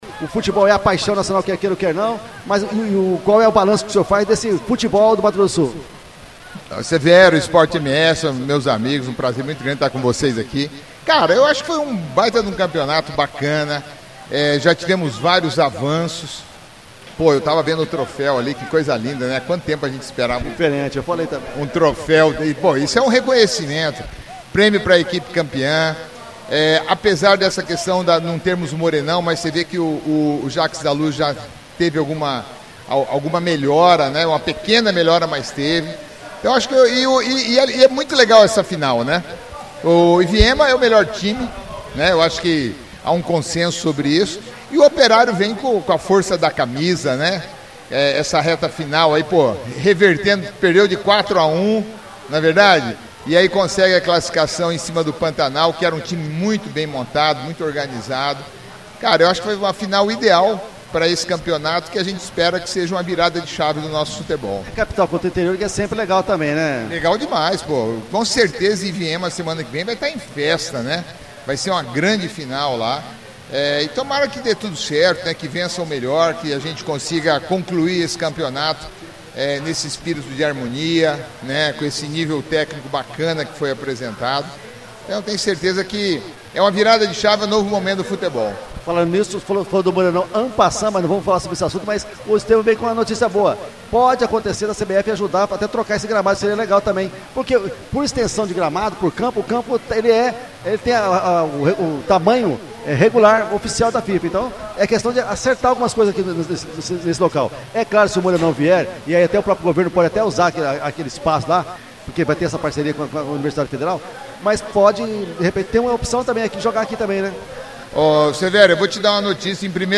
Secretário falou ao microfone da Rádio EsporteMS no último domingo (print-EMS) Reunião ocorrida entre representantes do governo e prefeitura entra em um acordo para dar uma reforma estruturante no estádio Jacques da Luz.